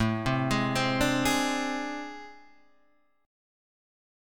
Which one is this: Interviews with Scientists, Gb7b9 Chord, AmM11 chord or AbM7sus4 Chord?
AmM11 chord